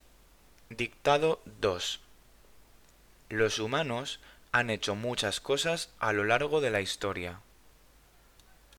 Dictado 2